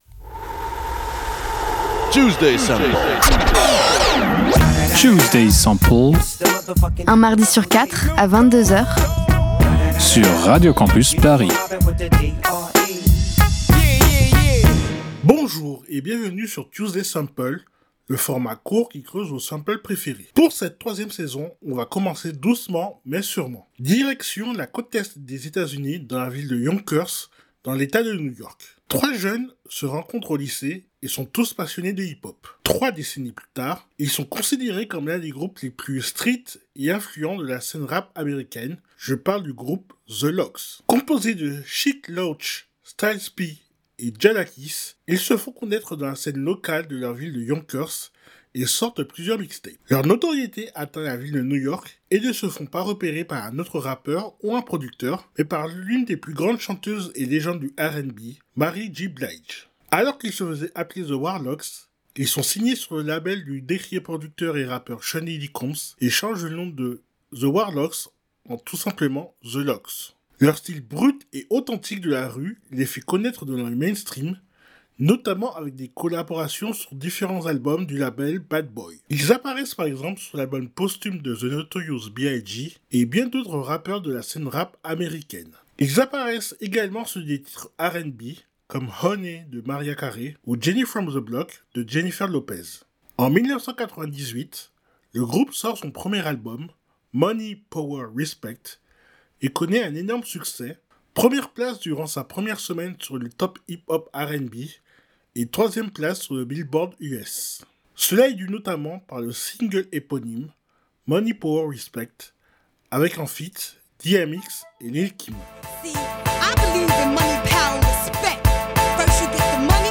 Type Musicale Hip-hop